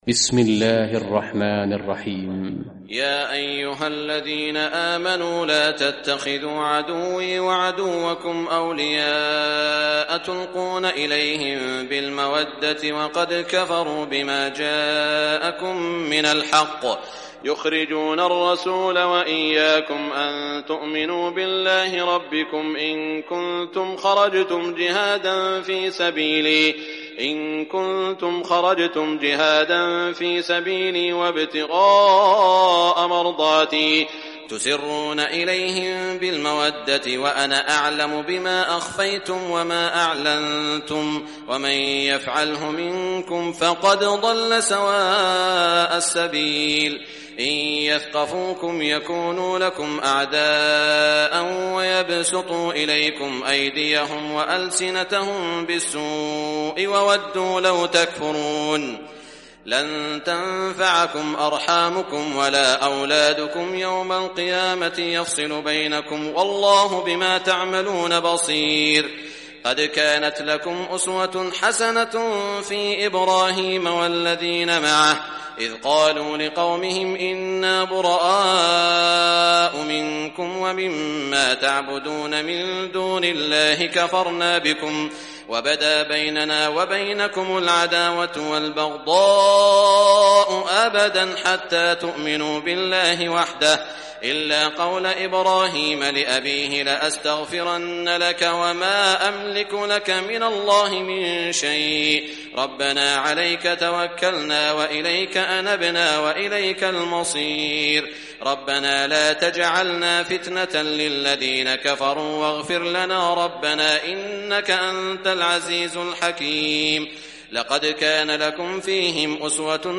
Surah Al-Mumtahanah Recitation by Sheikh Shuraim
Surah Al-Mumtahanah, listen or play online mp3 tilawat / recitation in Arabic in the beautiful voice of Sheikh Saud al Shuraim.